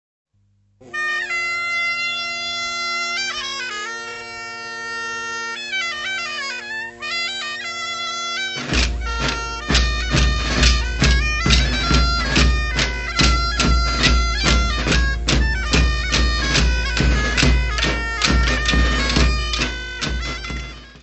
Folclore português : Trás-os-Montes e Alto Douro
Grupo Folclórico Mirandês de Duas Igrejas